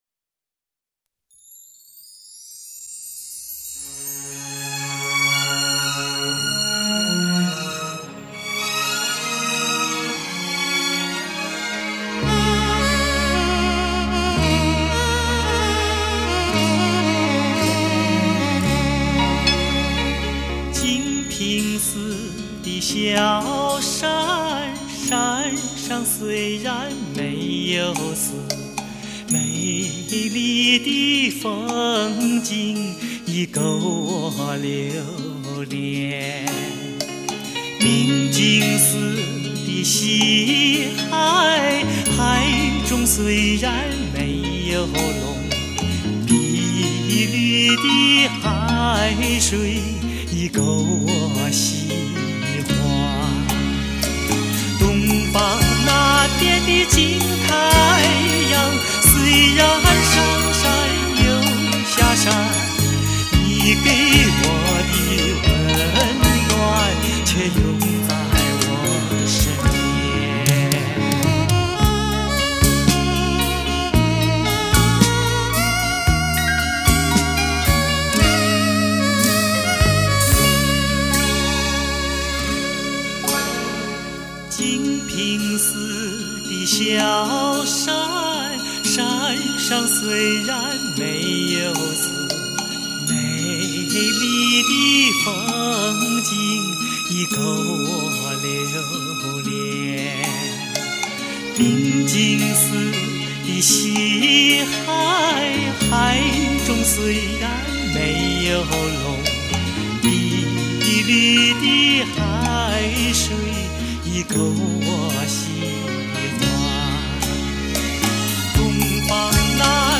甜润醇厚，吐字清晰。